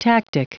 Prononciation du mot tactic en anglais (fichier audio)
Prononciation du mot : tactic